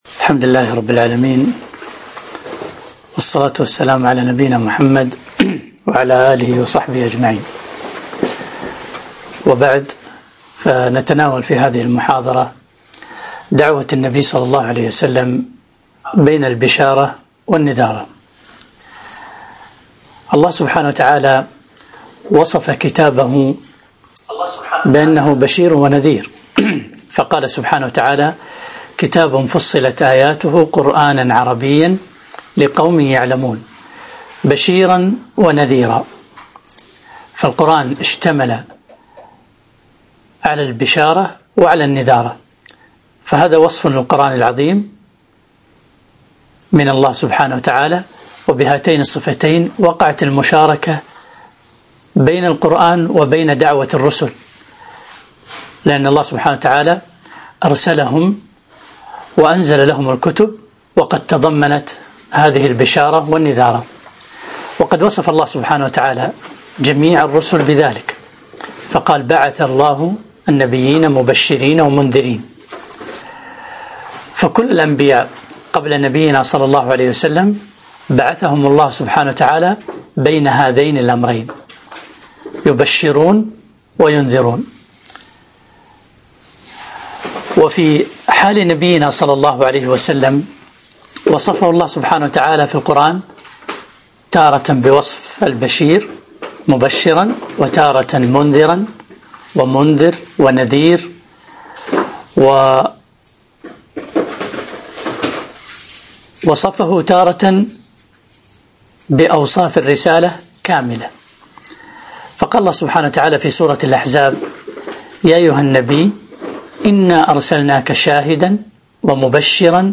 محاضرة - دعوة النبي ﷺ بين البشارة والنذارة